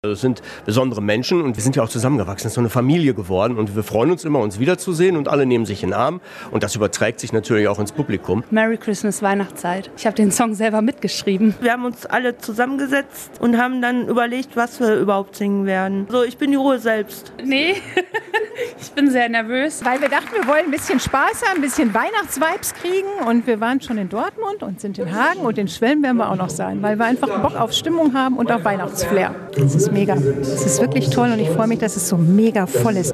Die Bethel Xmas Tour hat wieder Station in Hagen gemacht. Ein inklusiver Chor hat zusammen mit einer professionellen Liveband in der Matthäuskirche musiziert.
Ganz besondere Stimmung - im Publikum und backstage bei den Künstlern.
Reportage